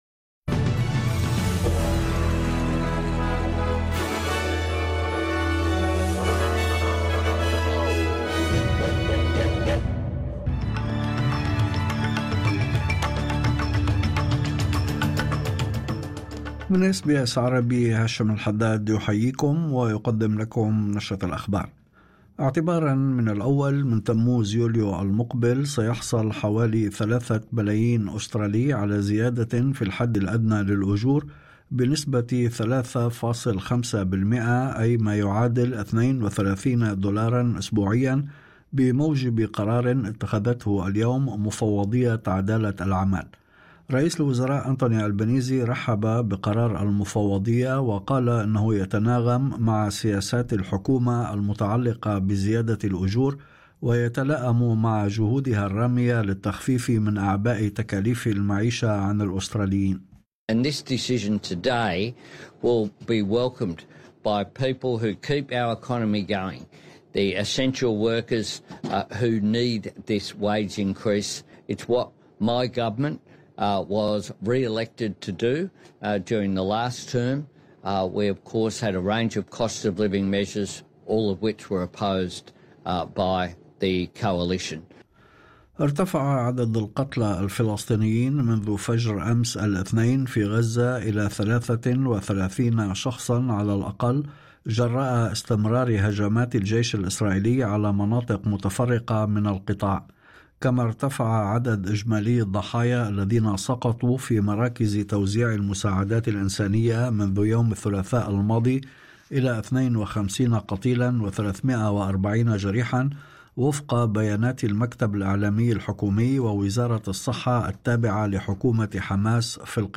نشرة أخبار الظهيرة 03/06/2025